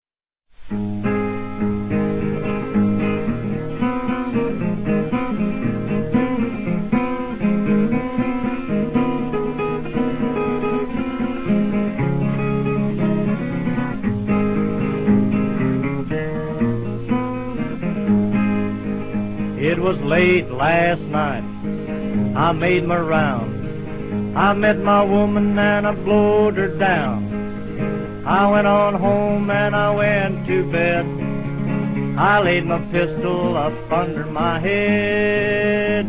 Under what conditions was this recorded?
Recorded in New York between 1944 and 1949.